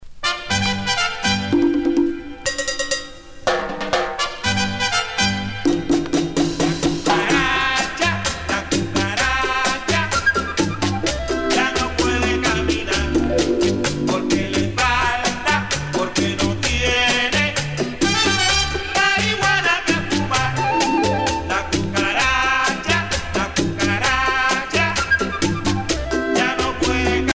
danse : cha cha cha
Pièce musicale éditée